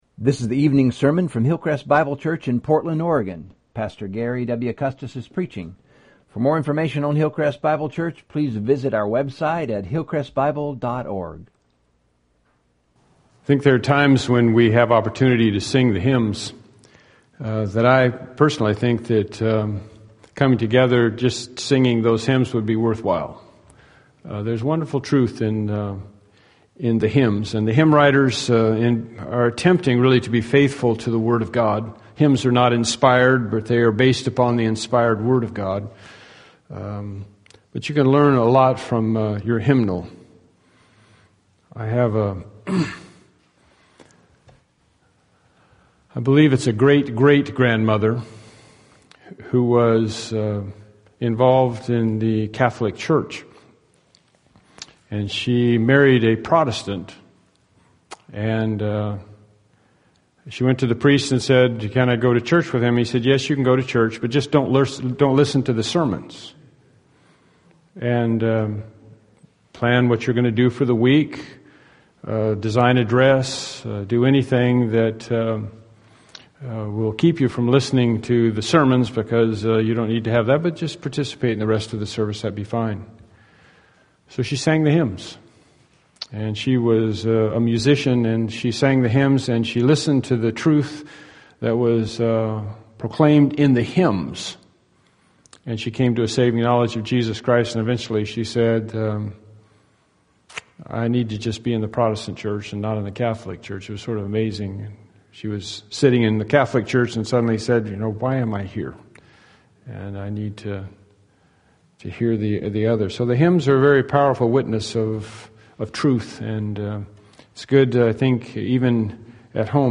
Series: Evening Sermons